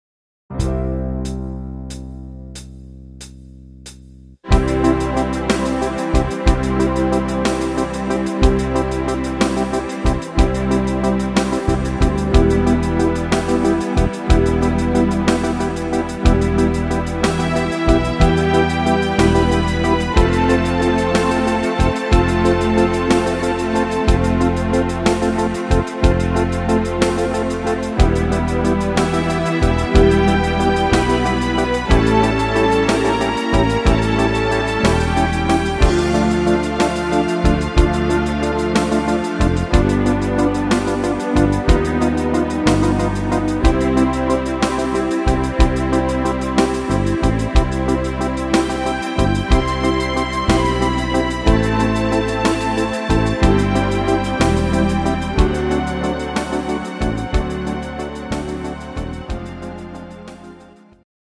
Easy to sing - C-Dur